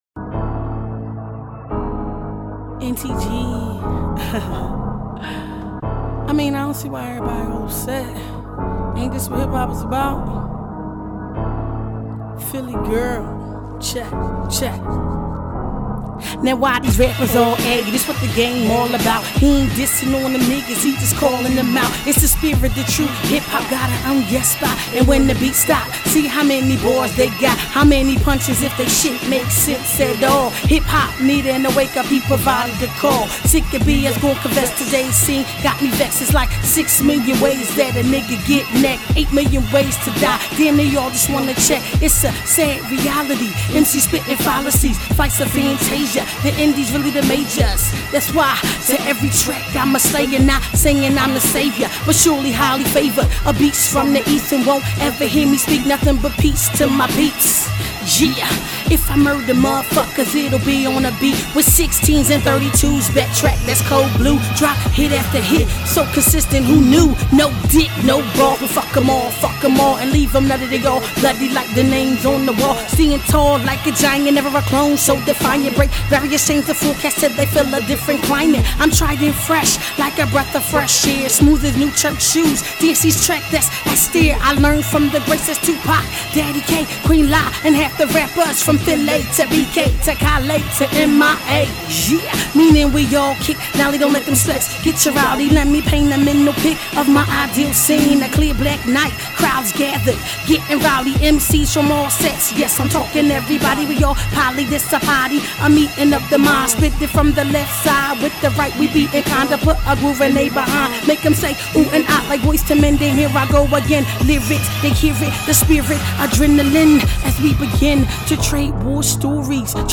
Hiphop Posted